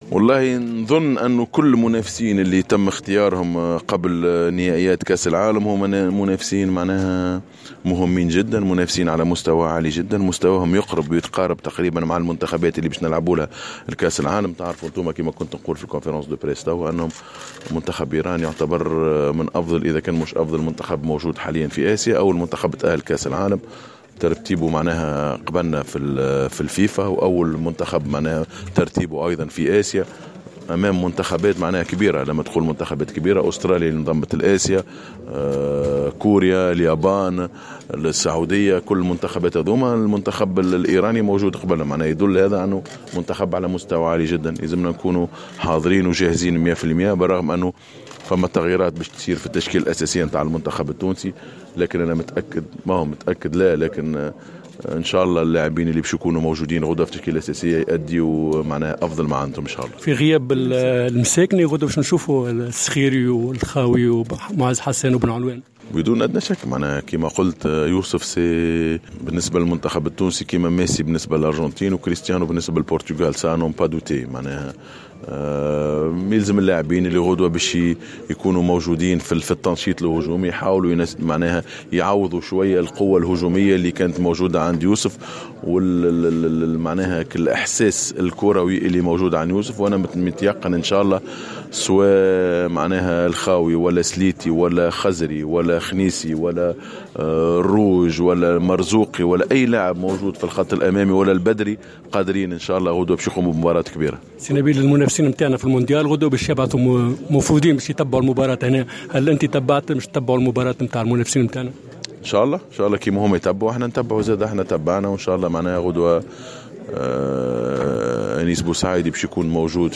عقد المدرب الوطني نبيل معلول ندوة صحفية اليوم الخميس 22 مارس 2018 بقاعة الندوات بملعب رادس للحديث حول المقابلة الودية التي ستجمع نسور قرطاج يوم الجمعة 23 مارس 2018 بداية من الساعة 19:15 بالمنتخب الإيراني في إطار التحضيرات لمونديال روسيا 2018.